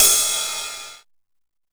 TR 909 Cymbal 03.wav